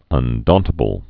(ŭn-dôntə-bəl, -dän-)